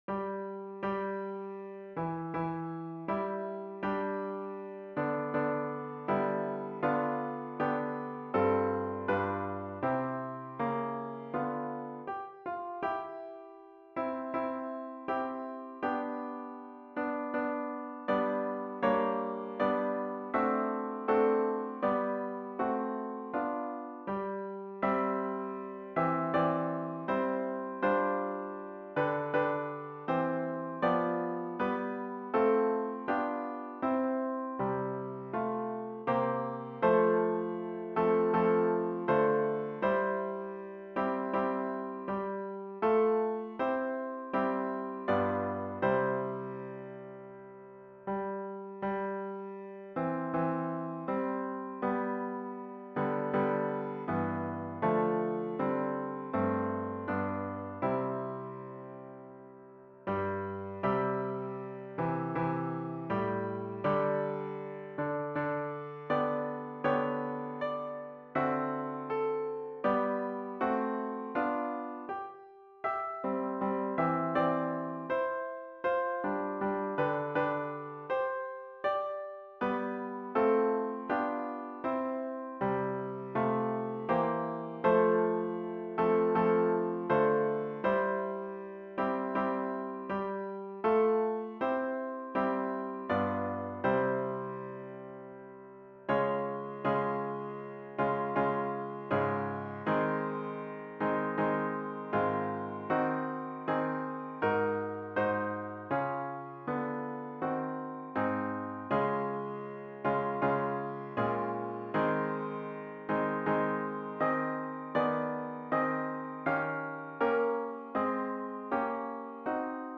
A capella arrangements for mixed chorus.
Grand ending.